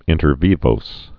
(ĭntər vēvōs, vī-)